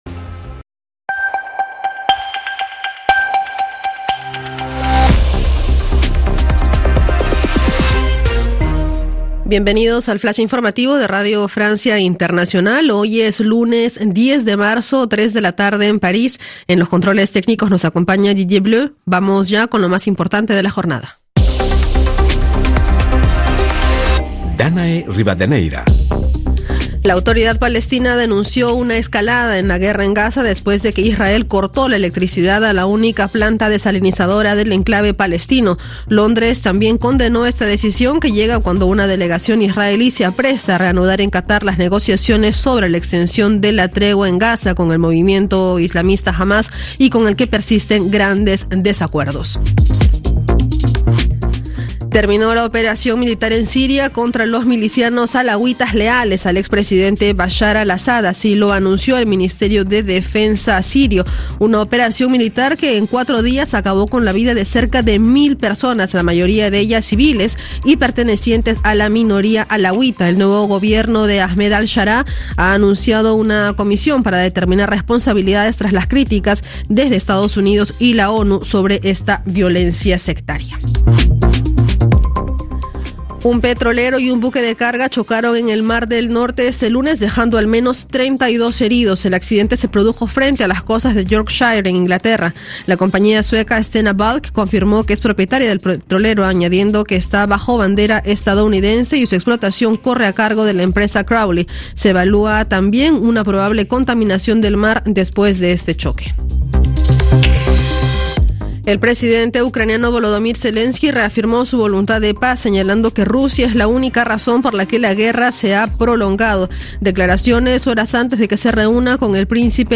Toda la actualidad con los noticieros de RFI - Informativo 10/03 14h00 GMT